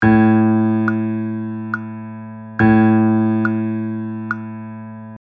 A2-70bpm-3_4.mp3